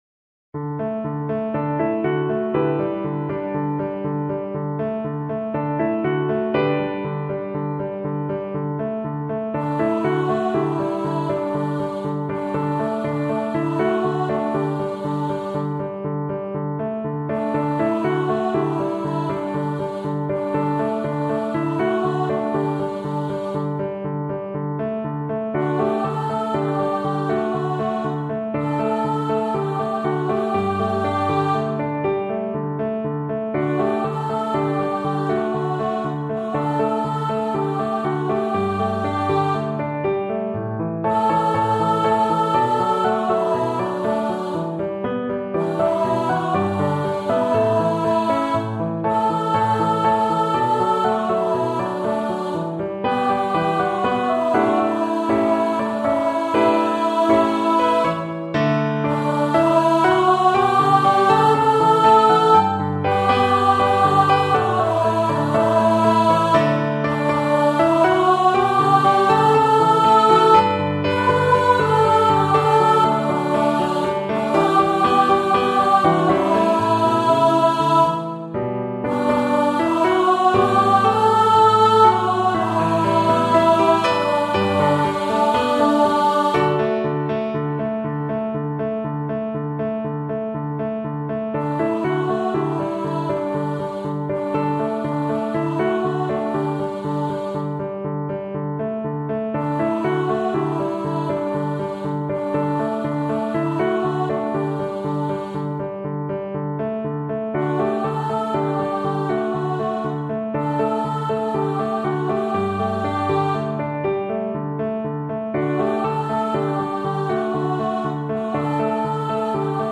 Unison with piano
MIDI demo